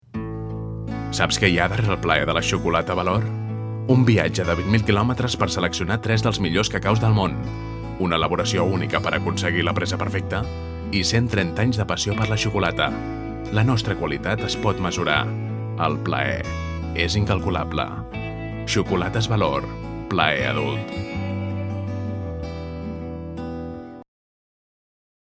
Voz masculina adulto joven. Timbre grabe, dulce y profundo. Posee una musicalidad sensual y atractiva, cálida, próxima, segura y natural
Sprechprobe: eLearning (Muttersprache):